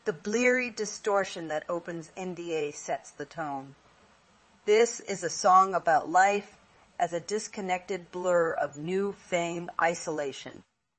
tortoise-tts - (QoL improvements for) a multi-voice TTS system trained with an emphasis on quality